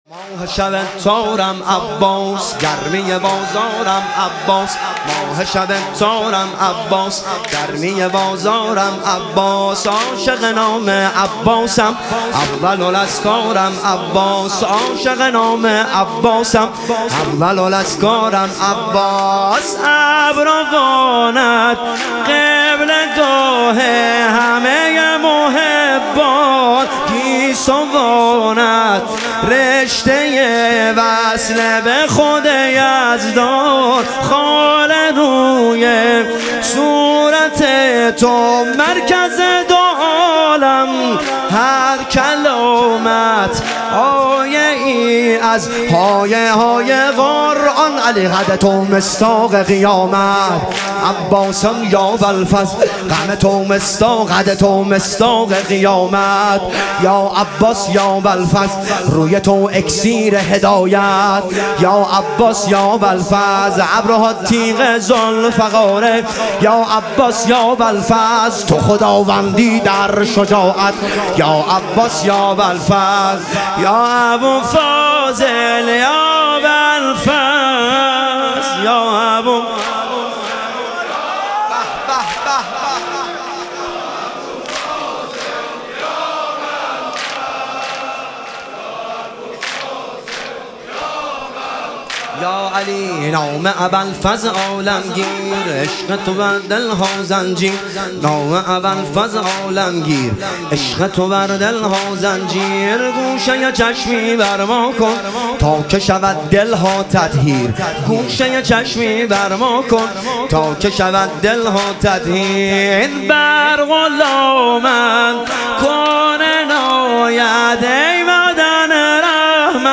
شهادت امام هادی علیه السلام - واحد